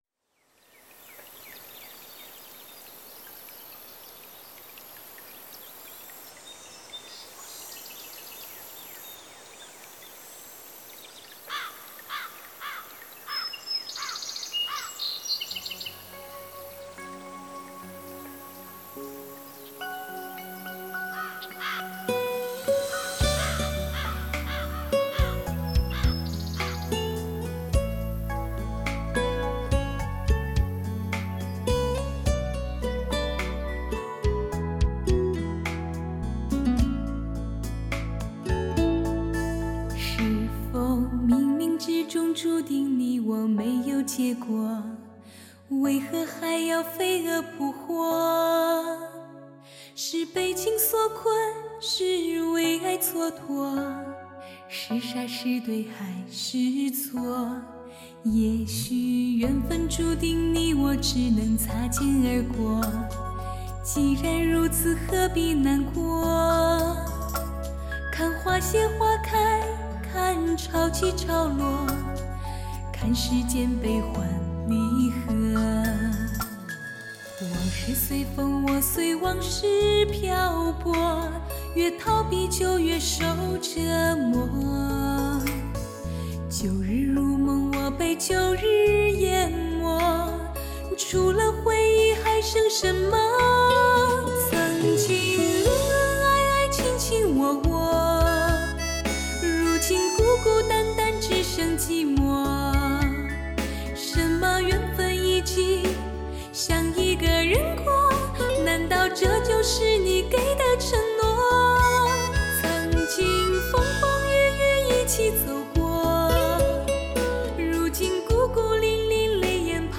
清纯和柔美的声音，演绎经典流行的完美组合，
无与伦比的磁场音效，震撼发烧天碟的无限魅力，这是声音的一次革命.
清纯柔美的嗓音将经典与流行完美的结合